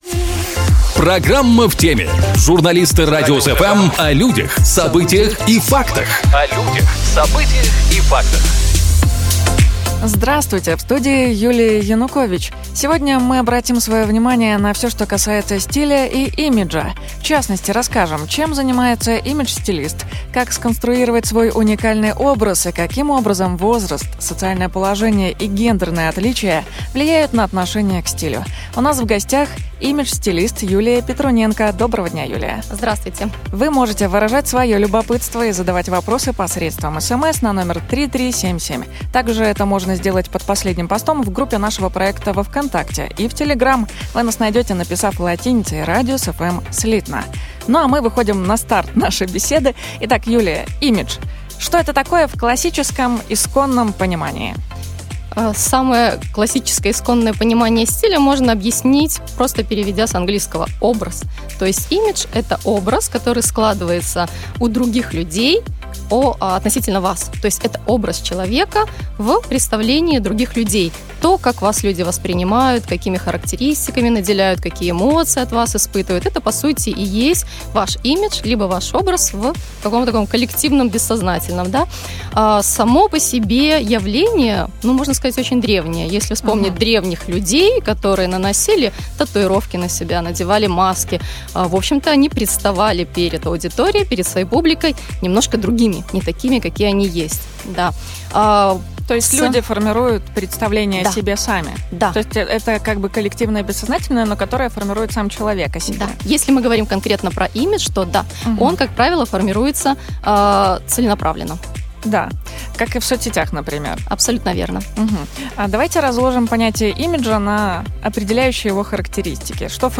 В студии "Радиус FM"